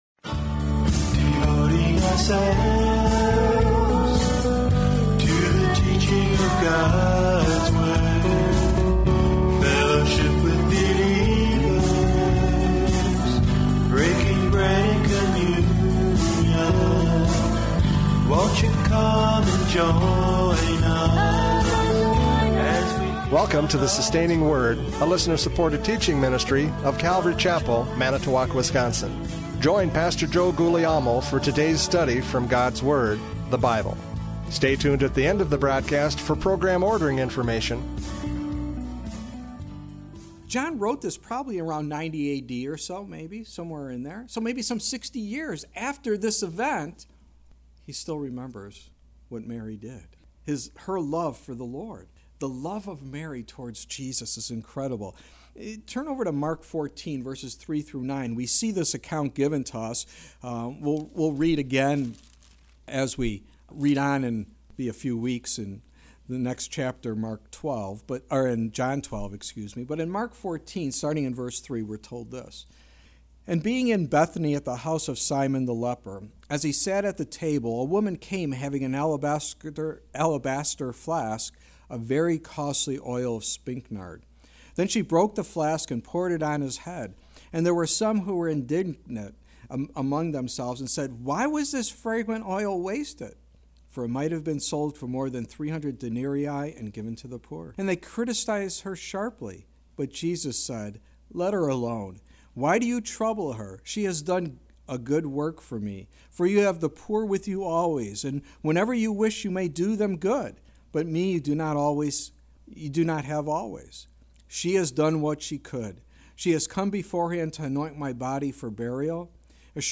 Radio Programs « John 11:1-4 The Glory of God!